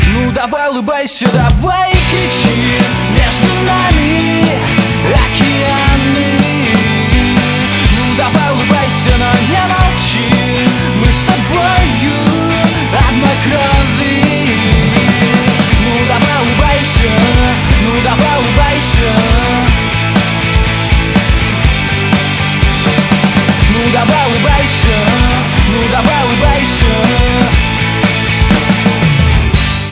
• Качество: 192, Stereo
рок